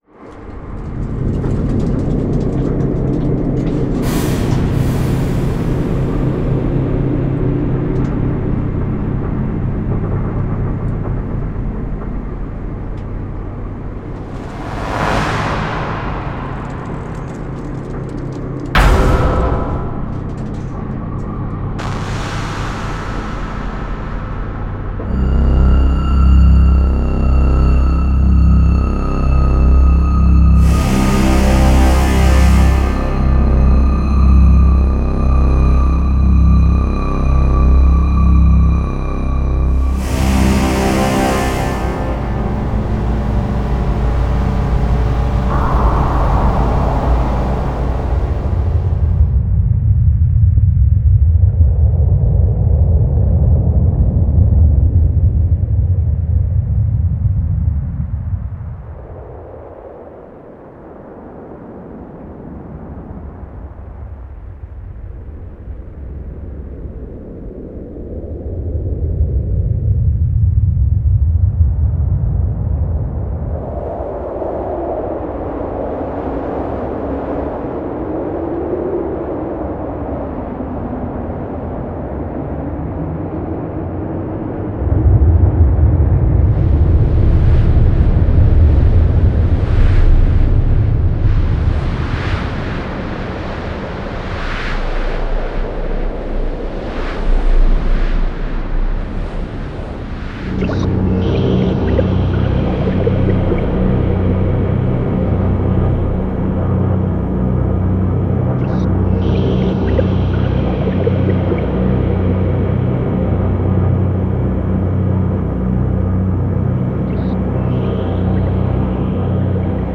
Download ominous atmospheres and dark sound effects.